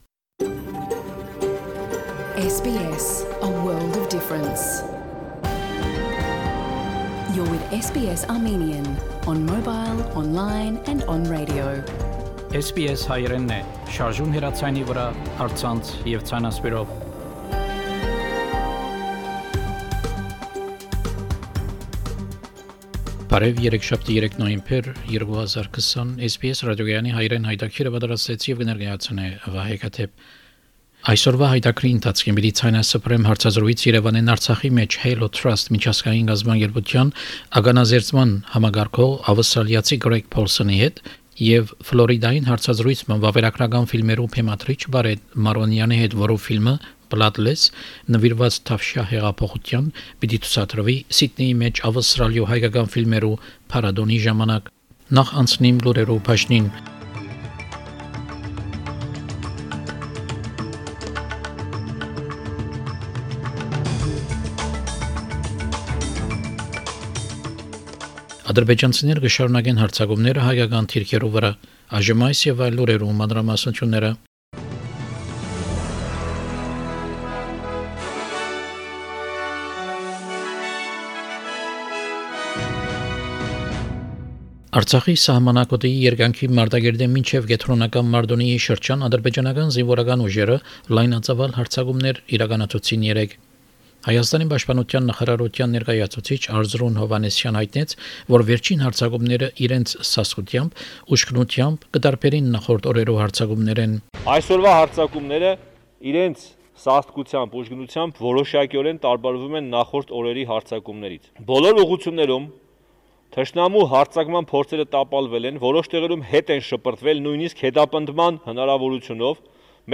SBS Armenian news bulletin – 3 November 2020
SBS Armenian news bulletin from 3 November 2020 program.